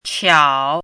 “巧”读音
qiǎo
国际音标：tɕʰiɑu˨˩˦
qiǎo.mp3